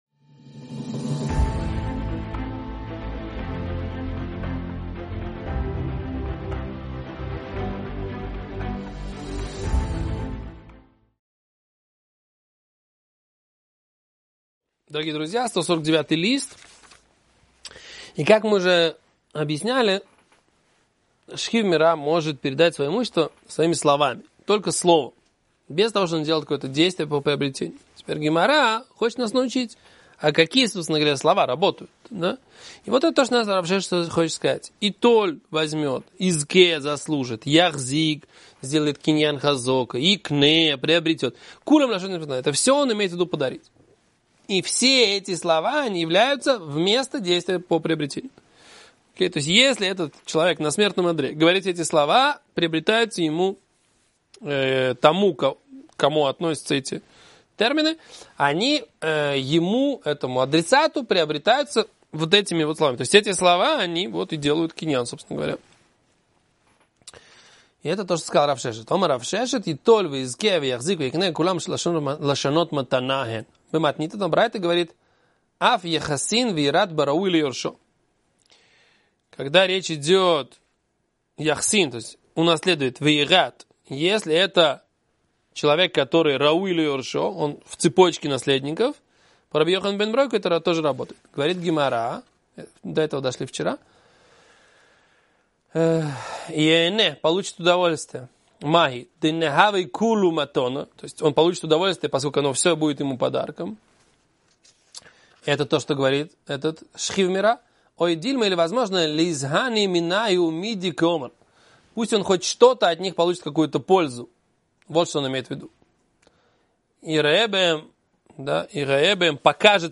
Урок